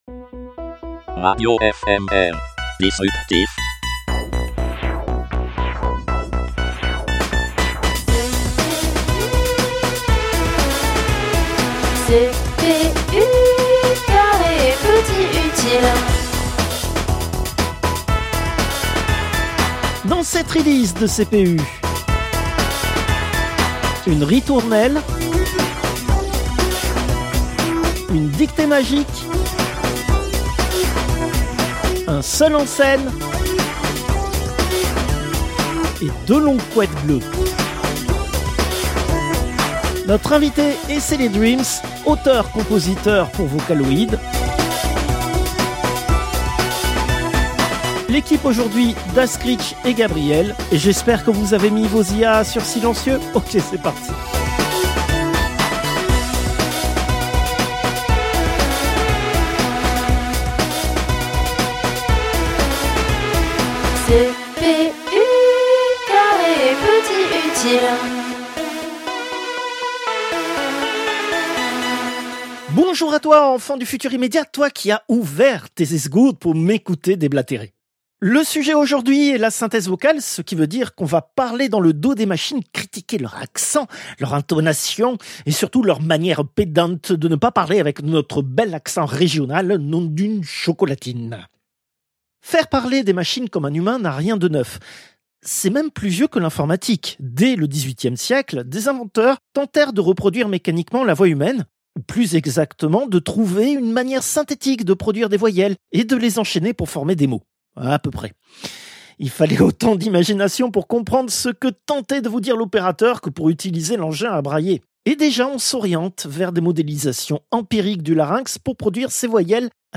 La suite sera diffusée dans 15 jours, l'intégralité de l'interview est déjà disponible dans son entièreté.